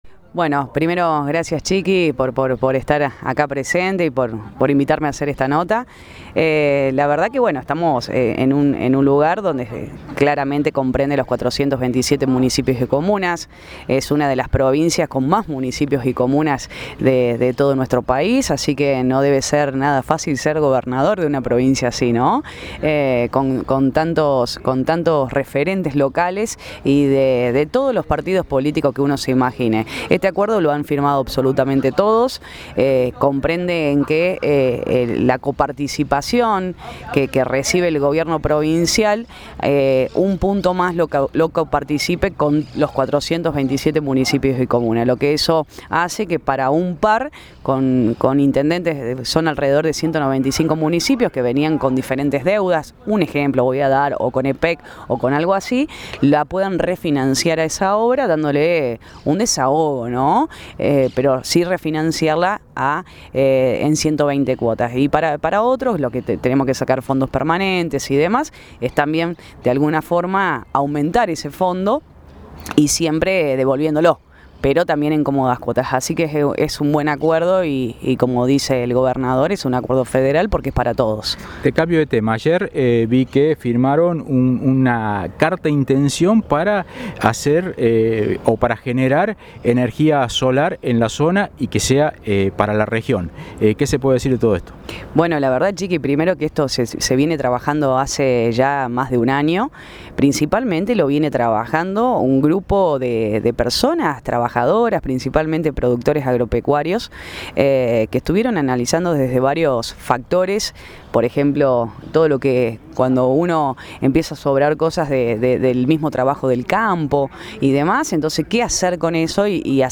Audio: Julieta Aquino (Intendenta de Inriville).